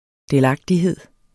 Udtale [ delˈɑgdiˌheðˀ ]